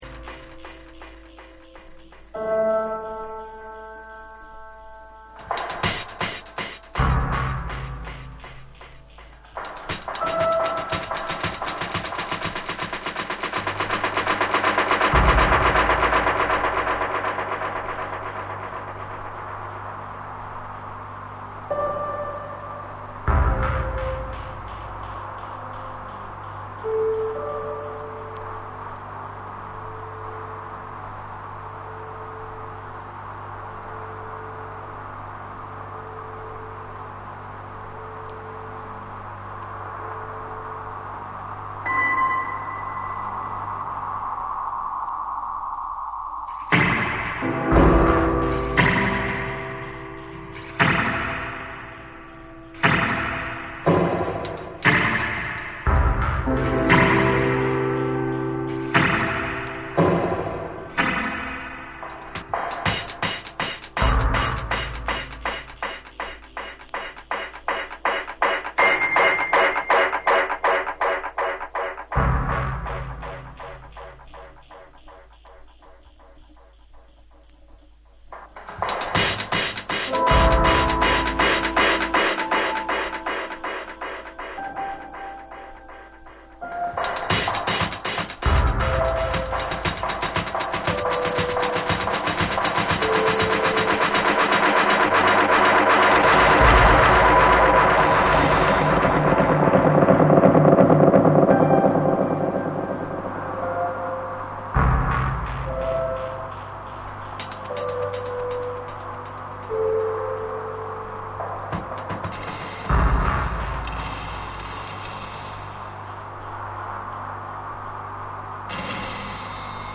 深い残響音と美しく響かせるピアノが、混沌とした世界観にドップリと陶酔させられてしまうエクスペリメンタル・ダブ
JAPANESE / REGGAE & DUB